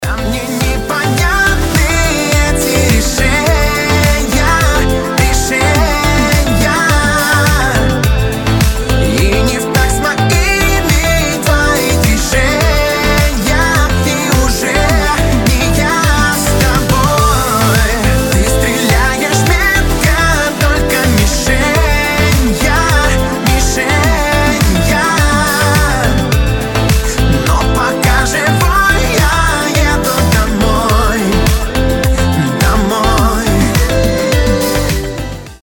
• Качество: 320, Stereo
поп
мужской вокал
dance